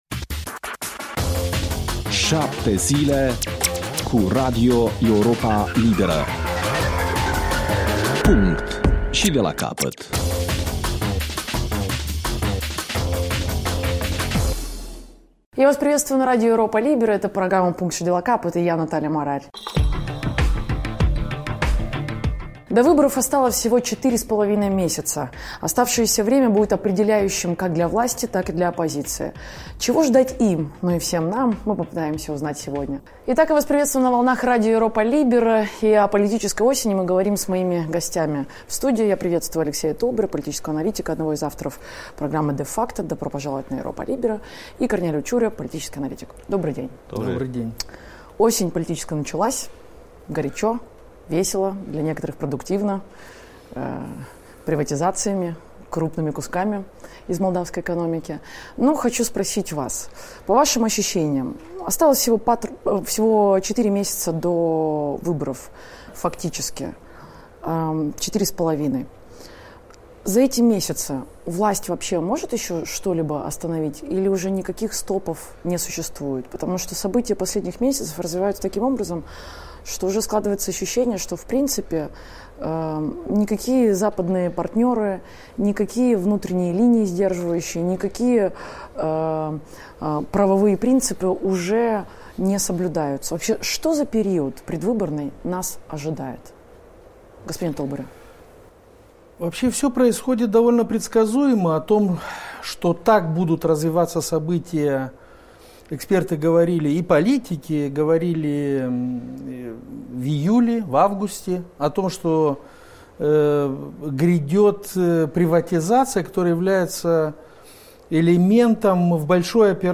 cu analiștii politici